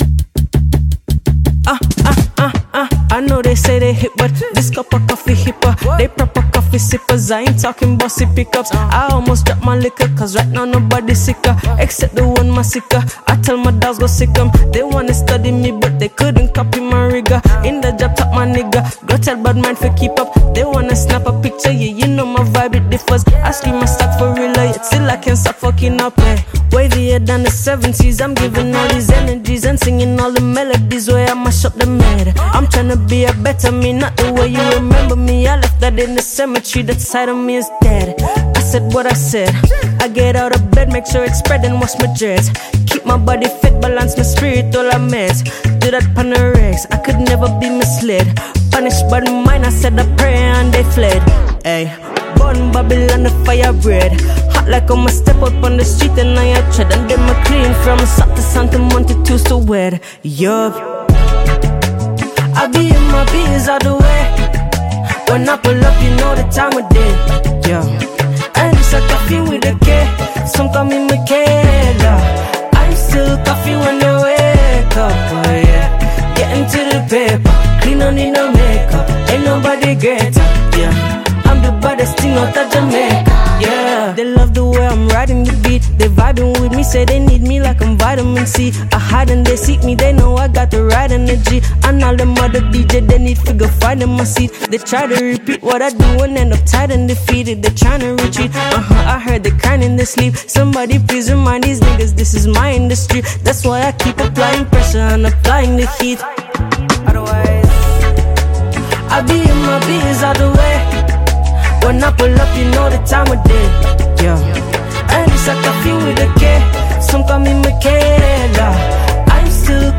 With its infectious rhythm and soulful vibe
Dancehall